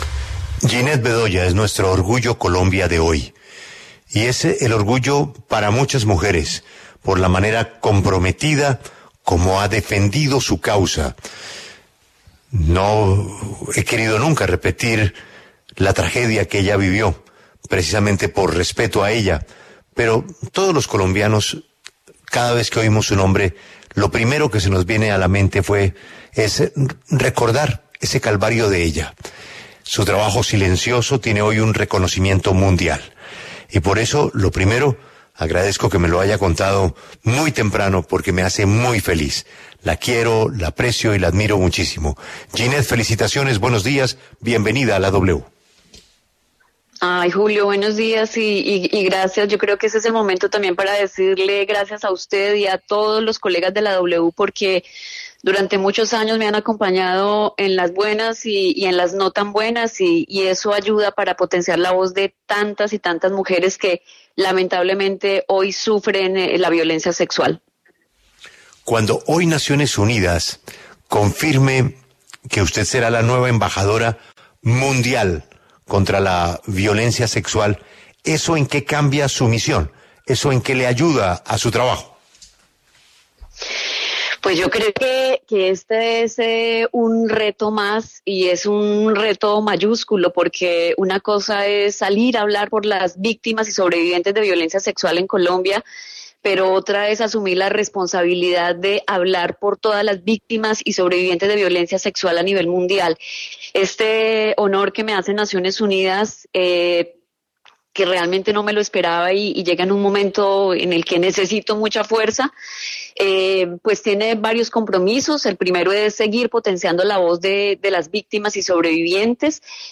En La W, la periodista Jineth Bedoya se pronunció sobre su nombramiento como embajadora mundial de la Lucha Contra la Violencia Sexual en la ONU.